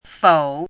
“否”读音
fǒu
国际音标：fou˨˩˦;/pʰi˨˩˦